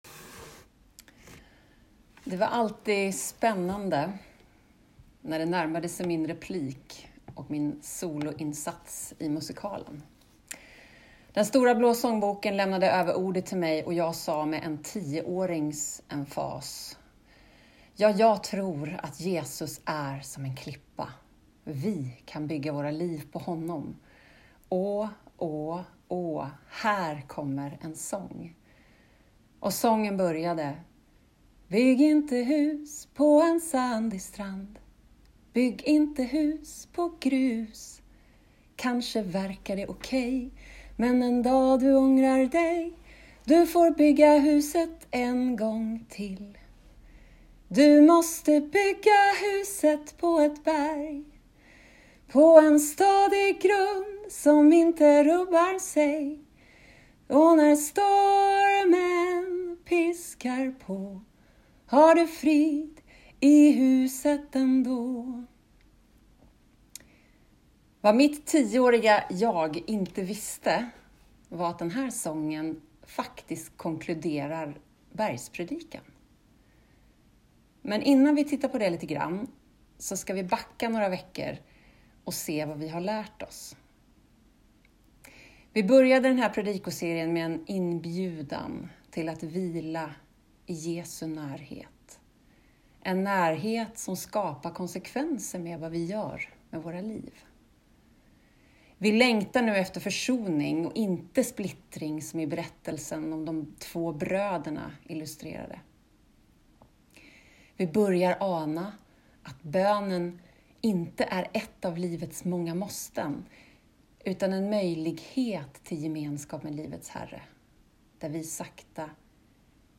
predikar